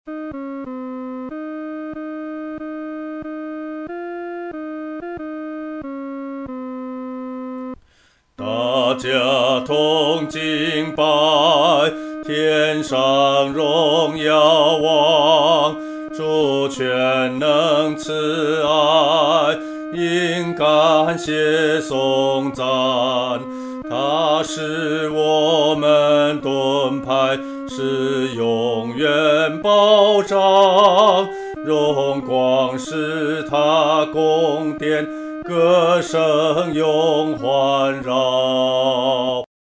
独唱（第二声）
来敬拜荣耀王-独唱（第二声）.mp3